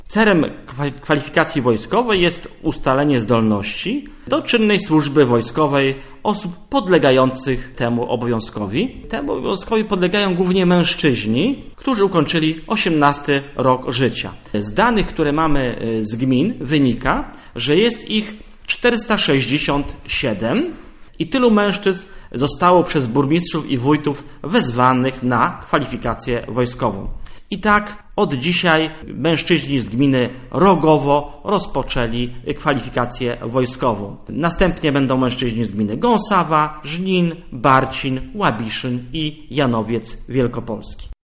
Mówi wicestarosta Andrzej Hłond.